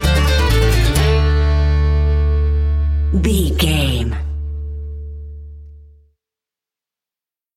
Ionian/Major
D
acoustic guitar
banjo
bass guitar
violin
Pop Country
country rock
bluegrass
happy
uplifting
driving
high energy